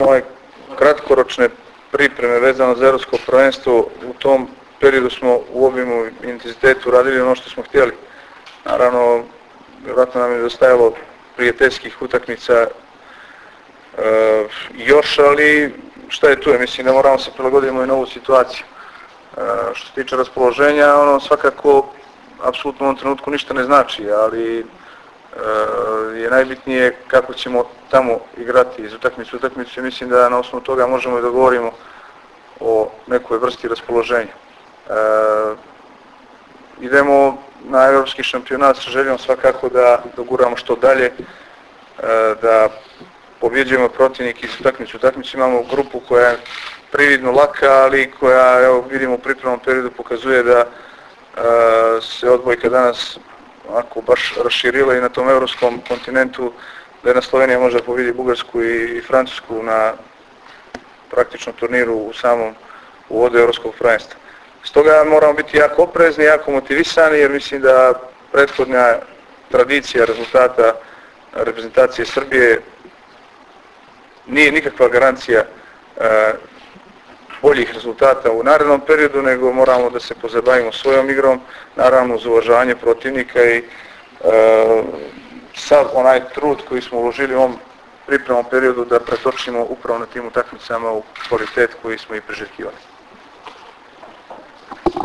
Tim povodom, danas je u beogradskom hotelu “M” održana konferencija za novinare, kojoj su prisustvovali Igor Kolaković, Ivan Miljković, Dragan Stanković i Vlado Petković.
IZJAVA IGORA KOLAKOVIĆA